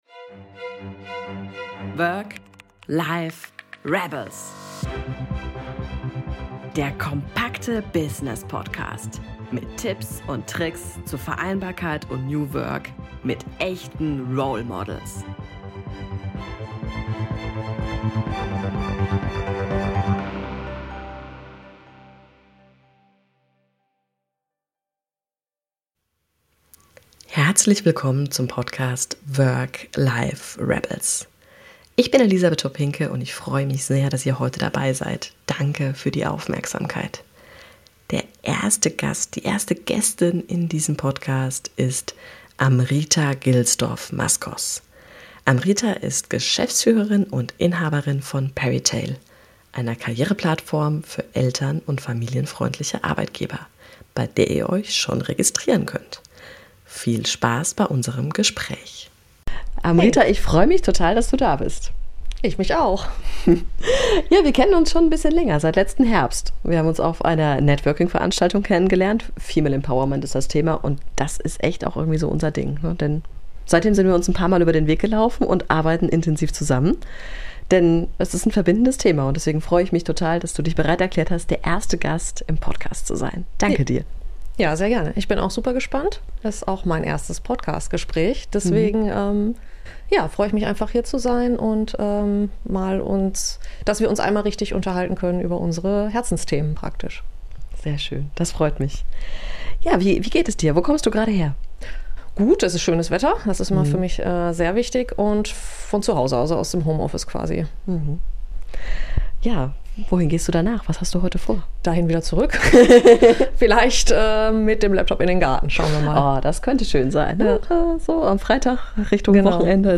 Das Interview gibt Inspiration und Mut dafür, dass ein einmal eingeschlagener Weg auch verändert werden kann.